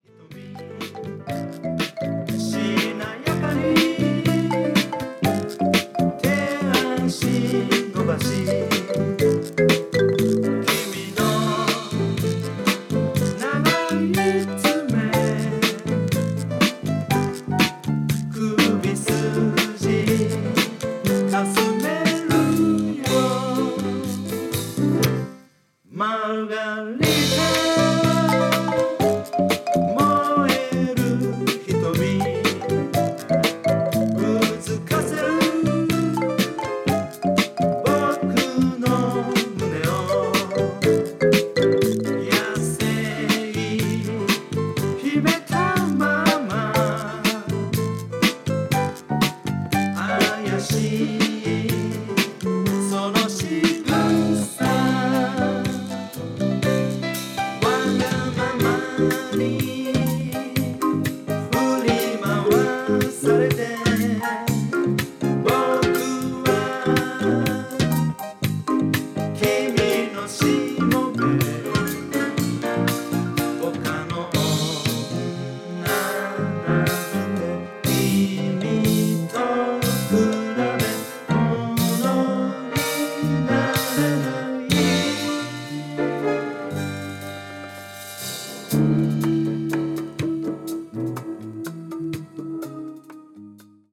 Condition Media : VG+(擦れ、チリチリ)